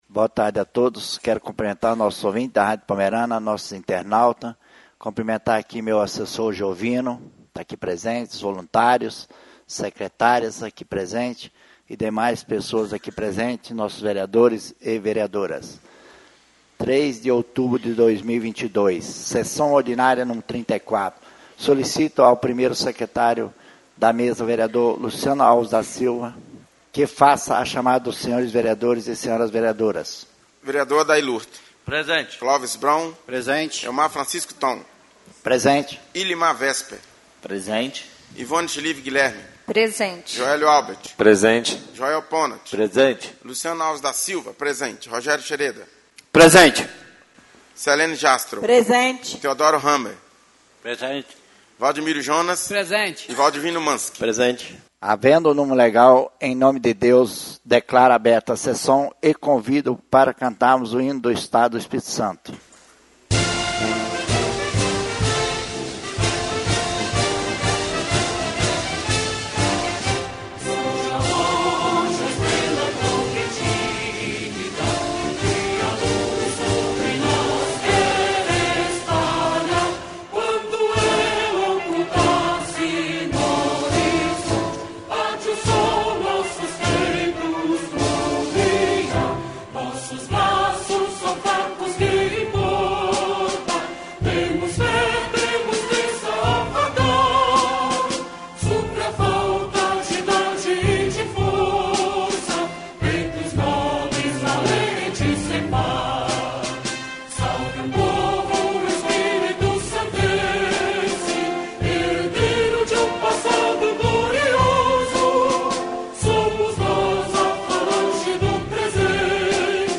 SESSÃO ORDINÁRIA Nº 34/2022